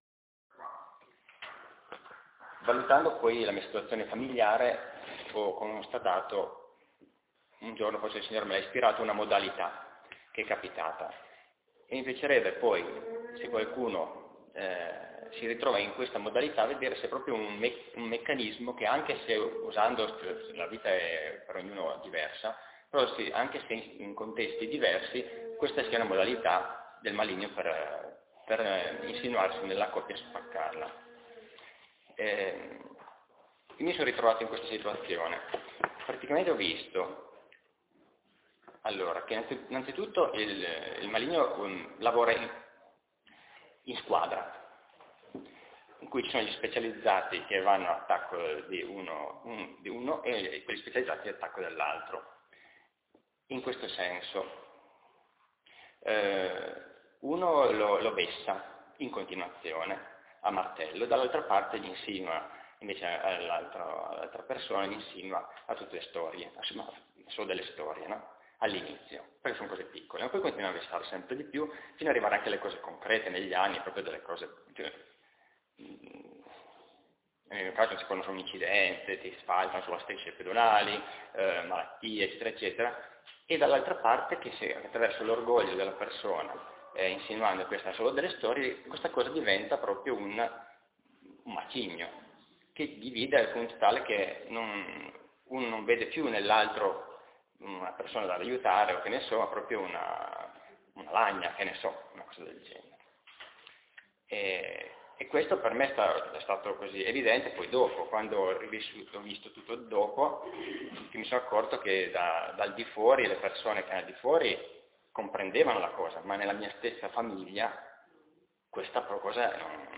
II convegno nazionale (2015)